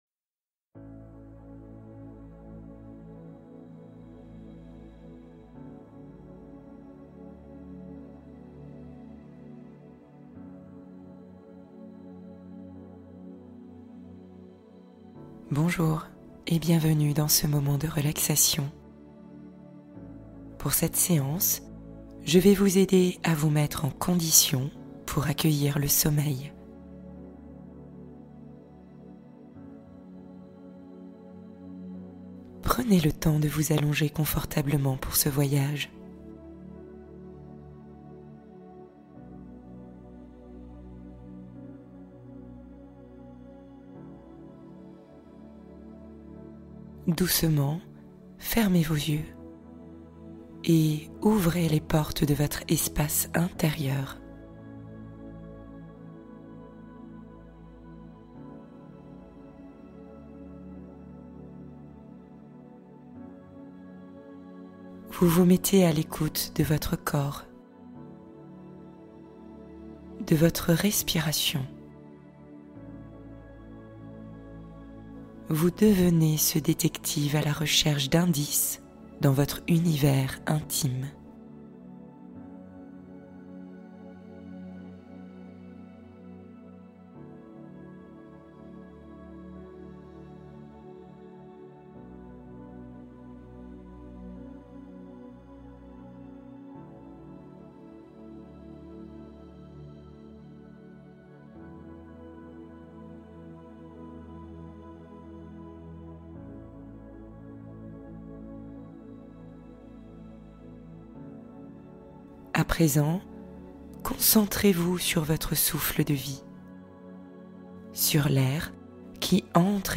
visualisation guidée